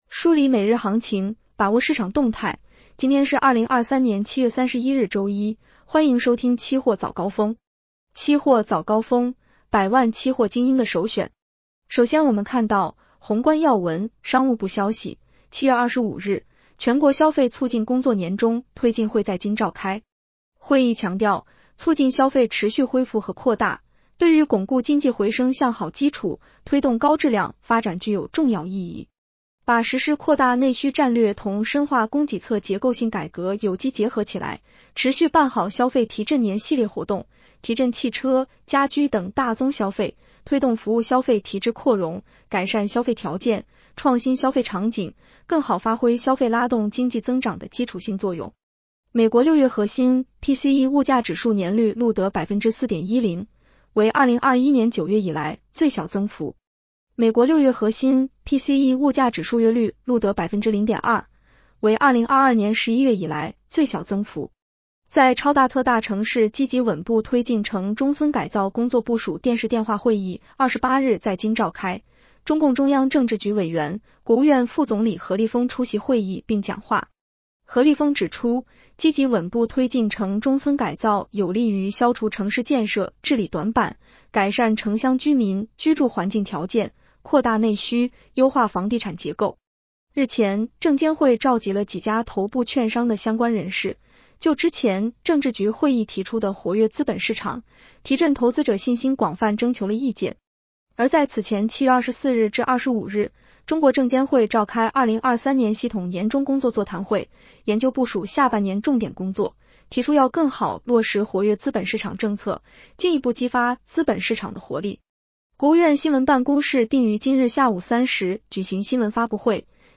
【期货早高峰-音频版】 女声普通话版 下载mp3 宏观要闻 1.